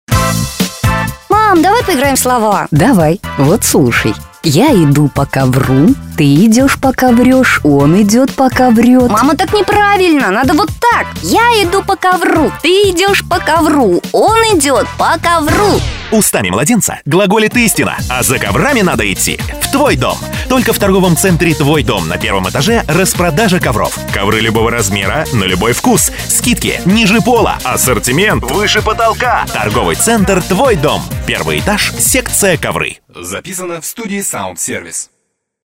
Юмор может быть ингредиентом любого типа аудиорекламы, но чаще всего он встречается в "срезах жизни" - драматизациях с участием актеров.
(пример 6 - рекламный радиоролик)